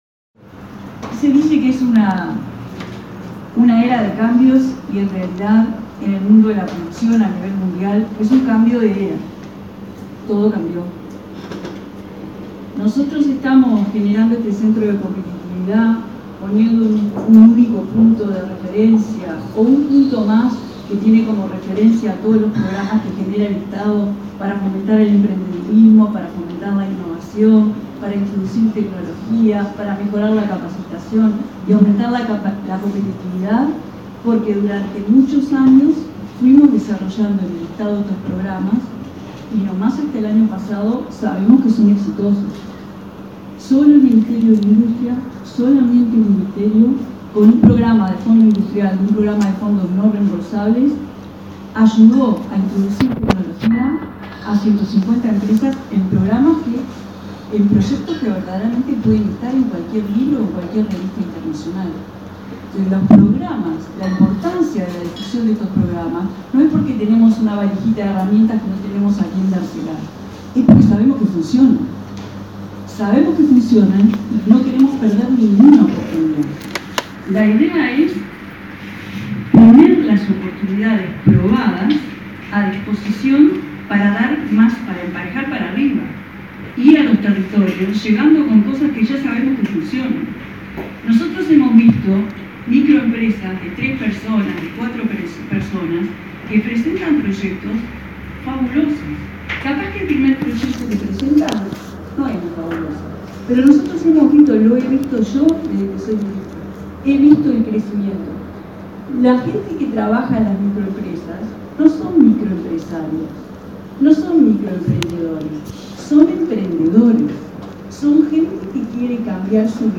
“Se generan oportunidades a micro, pequeñas y medianas empresas”, afirmó la ministra de Industria, Carolina Cosse, en la inauguración en Rivera del primer Centro de Competitividad del Uruguay. El Estado pone a disposición, de manera ordenada, 160 programas que brindan nueve agencias bajo el nombre Transforma Uruguay.